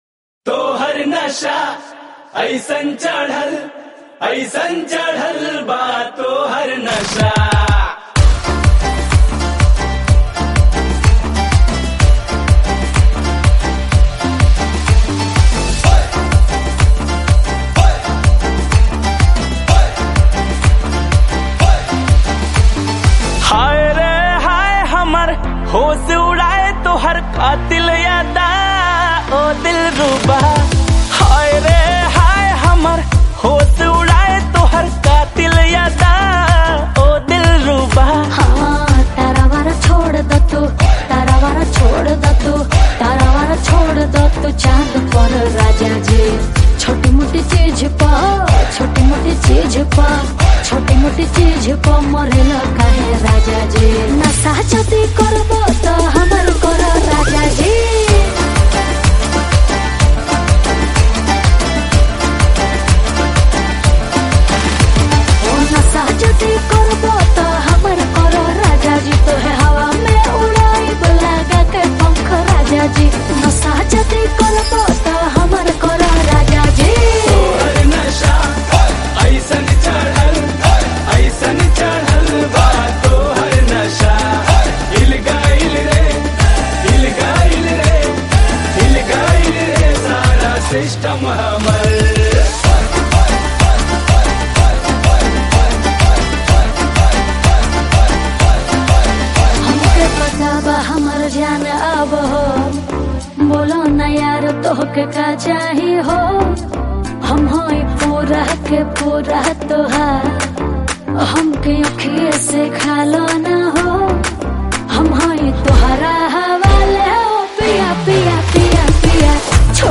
Category: Bhojpuri